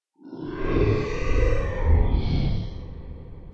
growl1.ogg